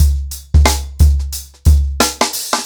TheStakeHouse-90BPM.41.wav